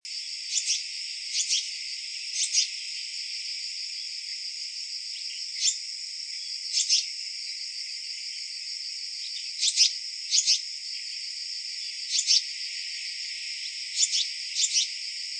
54-3鳥松2013黑枕藍鶲母1.WAV
黑枕藍鶲(台灣亞種) Hypothymis azurea oberholseri
高雄市 鳥松區 鳥松濕地
雜木林
鳴叫鳥隻(不藍)疑似雌鳥
Sennheiser 型號 ME 67